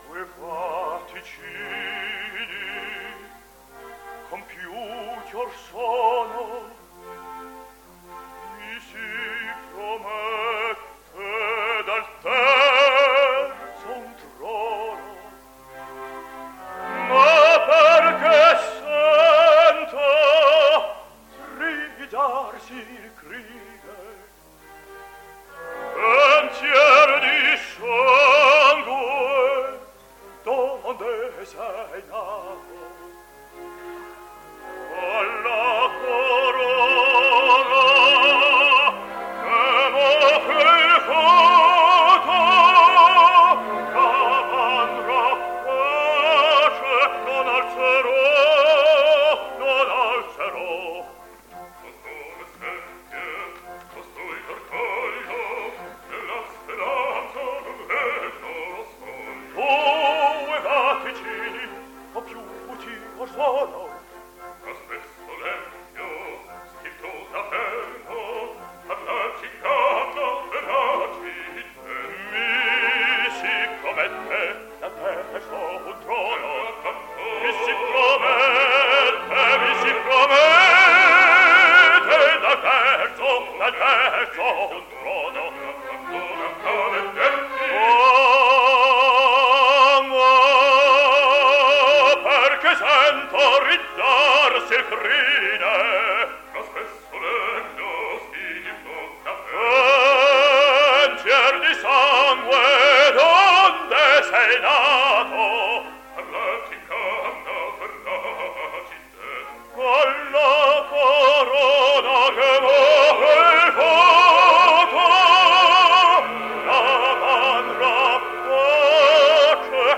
baryton
OPERA